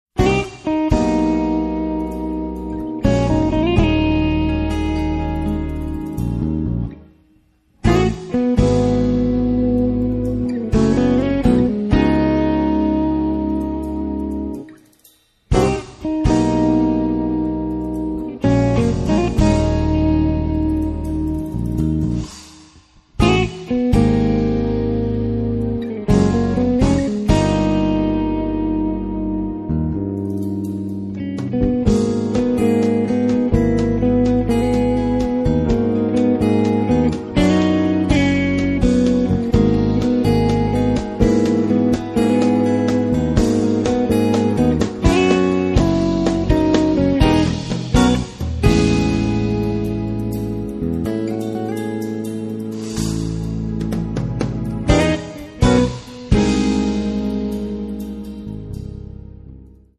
basso elettrico
That's fusion